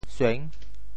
sueng6.mp3